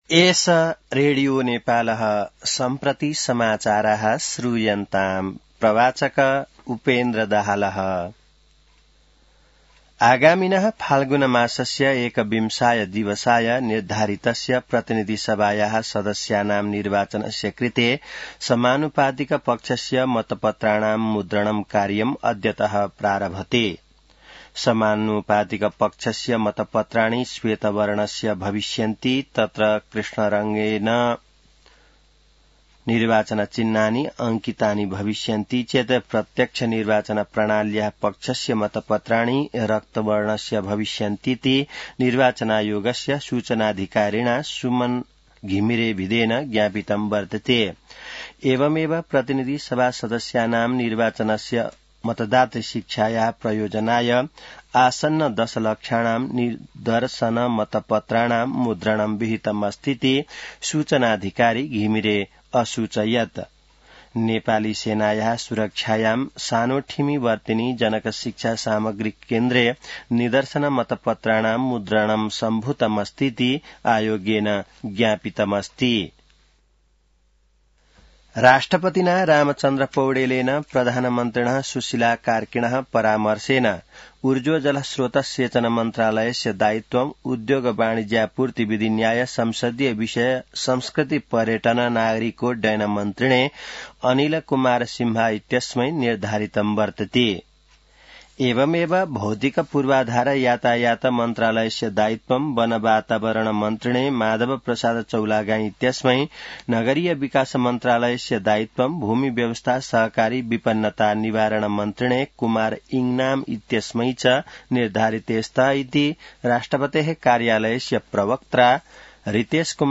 संस्कृत समाचार : २५ पुष , २०८२